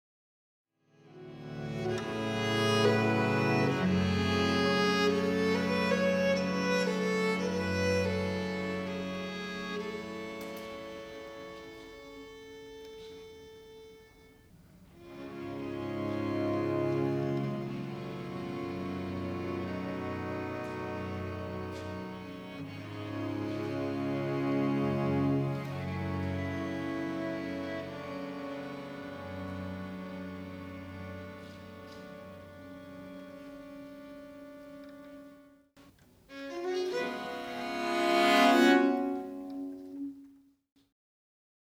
viola da gamba quartet (treble, tenor, 2 bass)